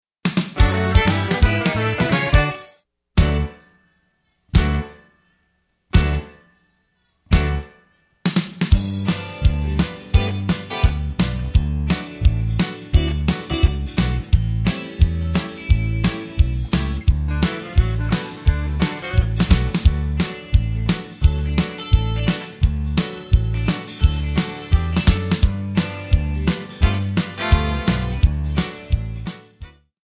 Backing track files: 1980s (763)